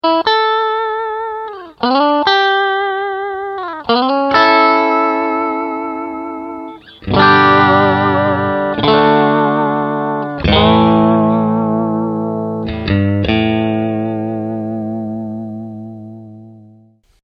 1954 eine Sensation - heute  Vintage : das Fender-Vibrato
Vintage-Vibrato
vintage_vib.mp3